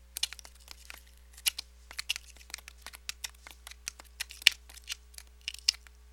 Звук скрежета и щелканья скорпиона